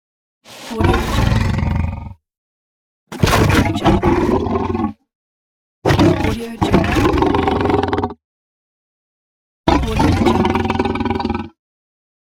Lion Efeito Sonoro: Soundboard Botão
Lion Botão de Som